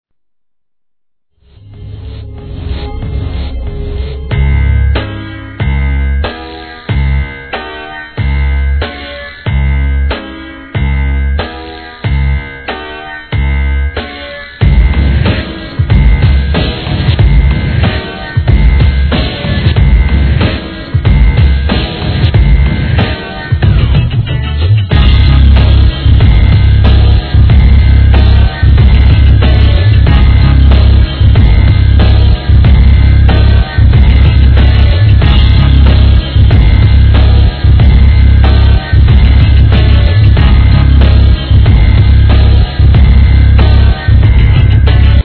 ドラムにシンセと混沌としたアブストラクトなブレークビーツが繰り広げる展開にぶっ飛ばされましょう!!